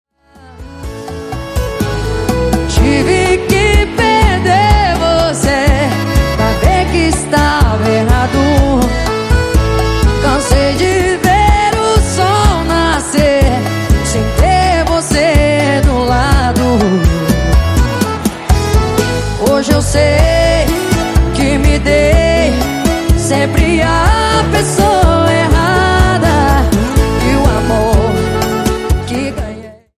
Brasileira